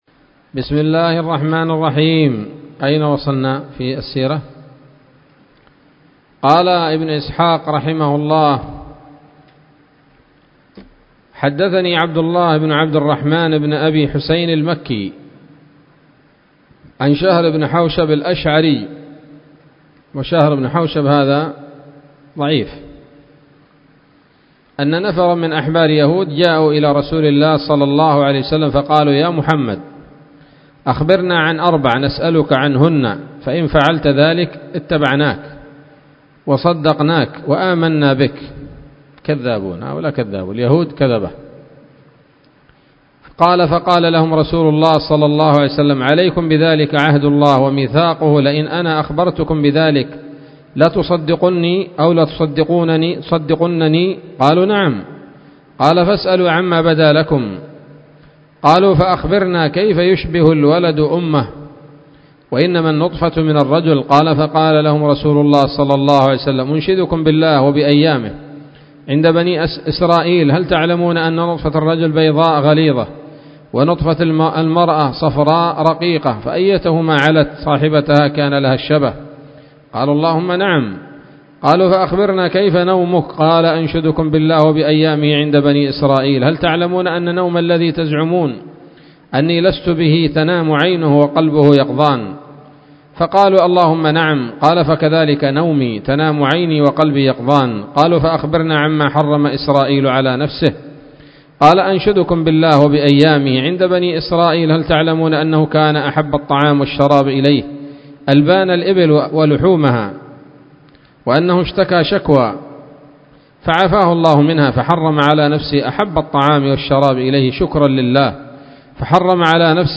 الدرس الحادي والتسعون من التعليق على كتاب السيرة النبوية لابن هشام